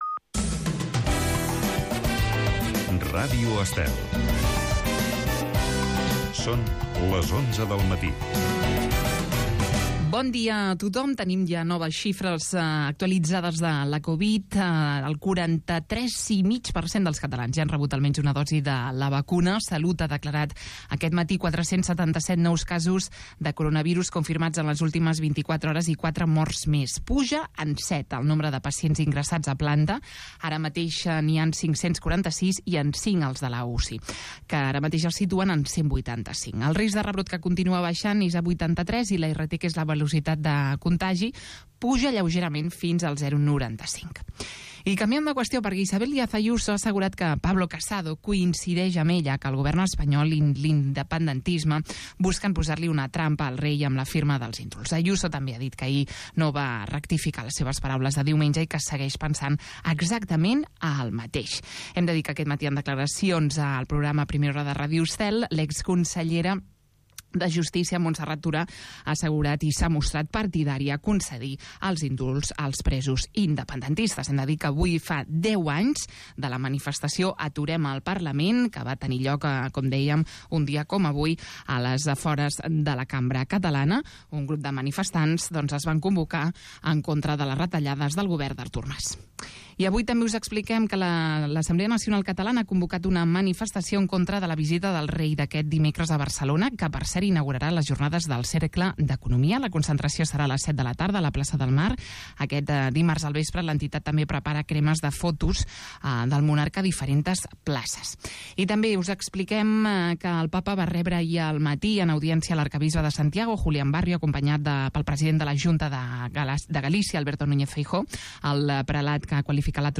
Espai amb d'entrevistes als protagonistes més destacats de cada sector, amenitzat amb la millor música dels 60, 70, 80 i 90